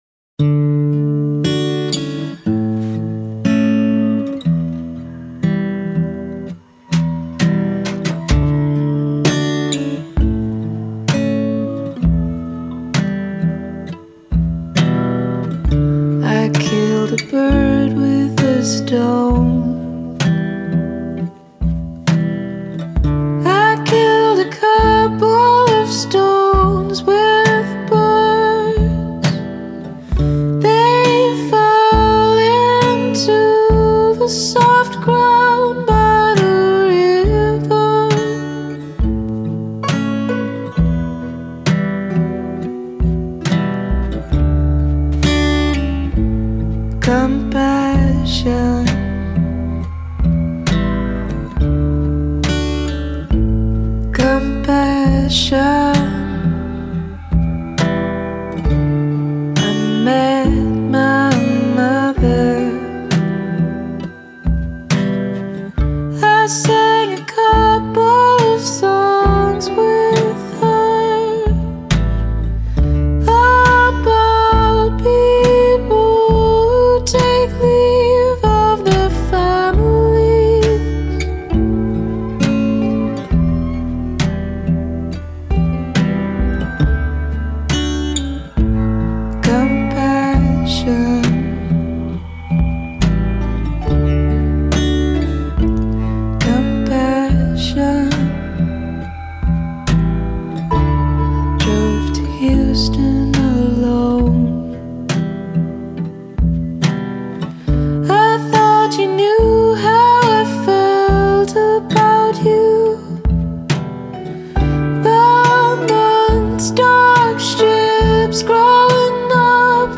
folksy album